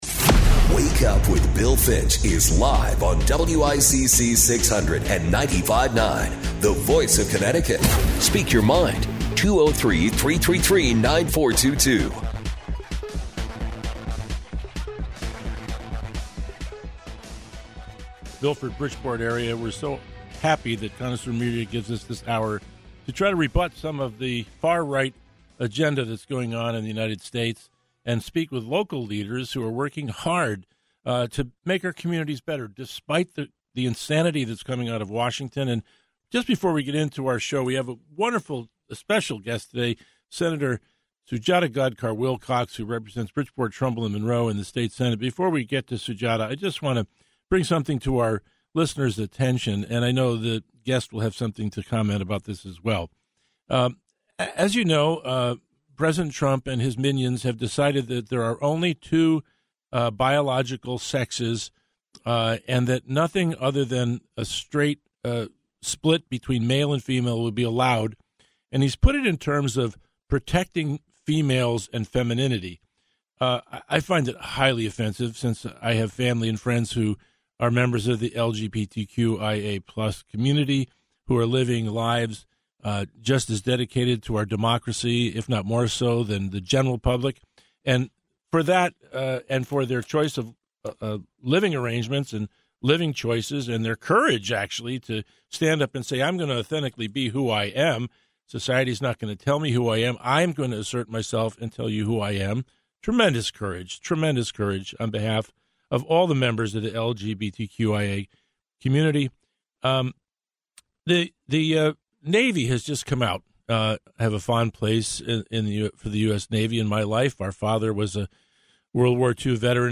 Connecticut State Senator Sujata Gadkar-Wilcox joins Bill for this week's show.